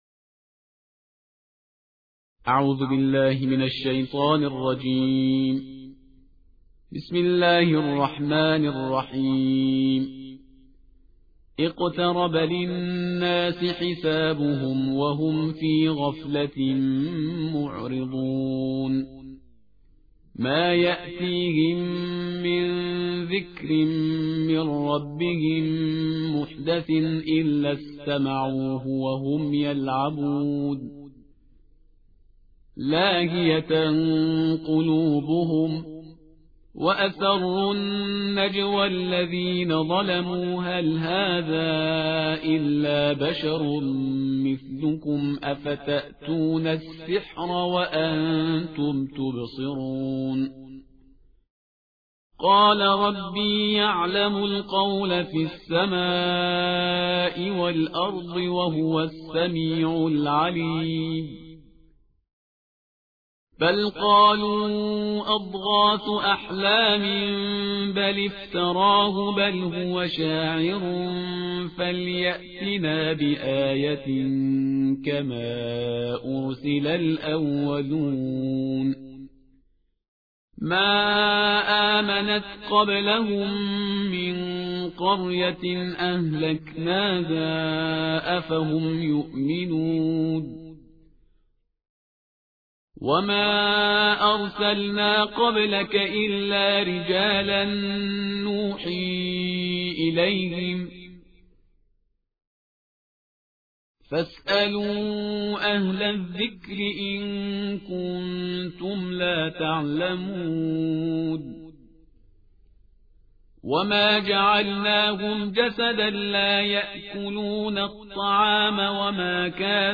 ترتیل جزءهفده قرآن کریم/استاد پرهیزگار